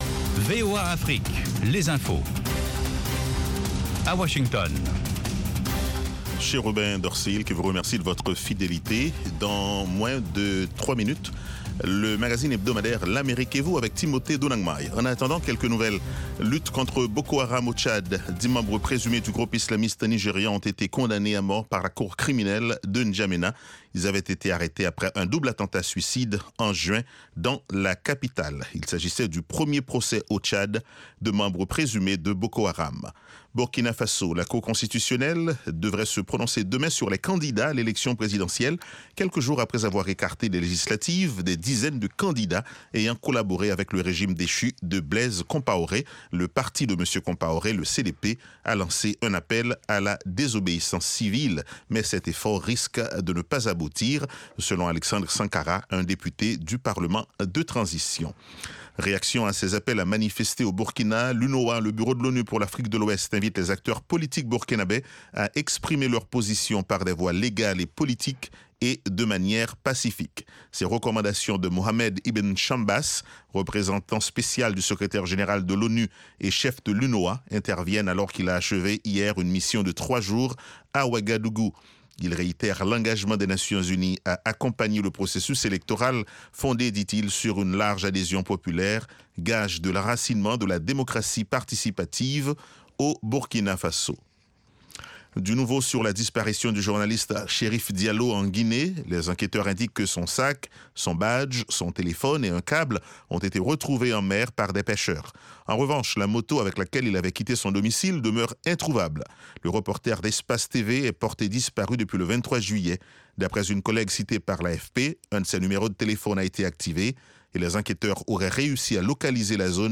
Bulletin
Newscast